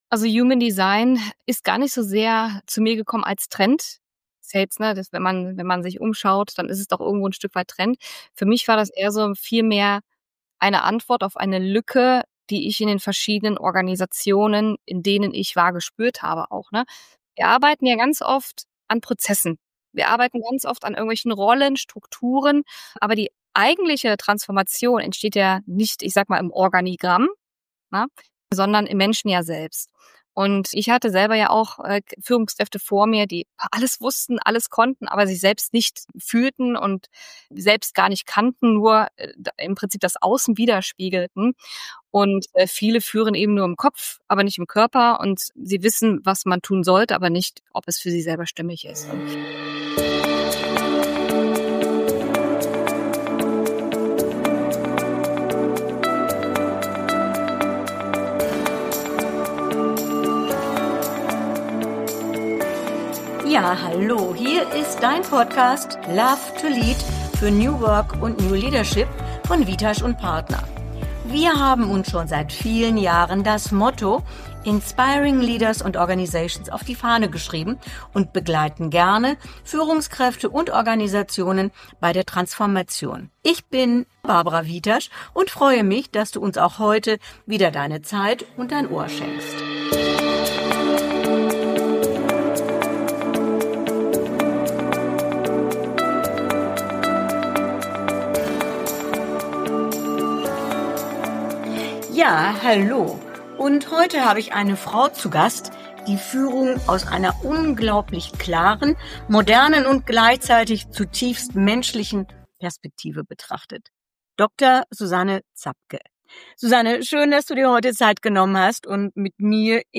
Ein Gespräch über innere Klarheit, echte Beziehungsgestaltung und Führung, die nicht angepasst, sondern stimmig ist.